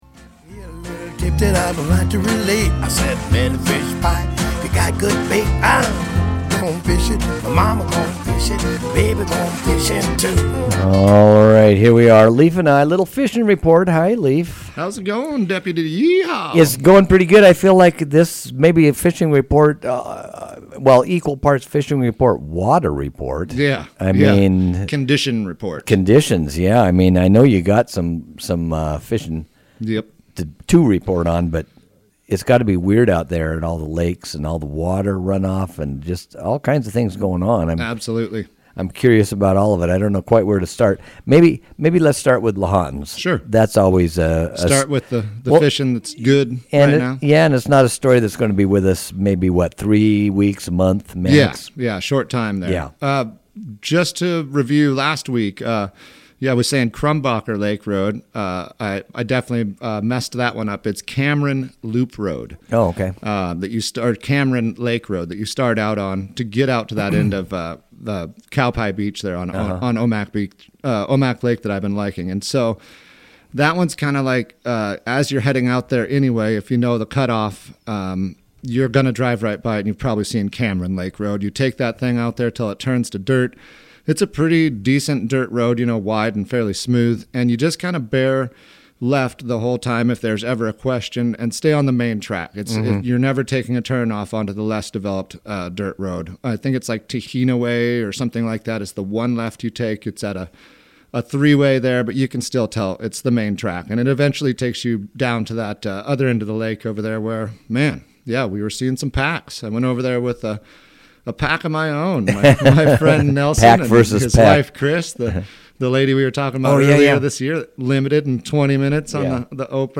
Fishing-Rpt.-4_15_16.mp3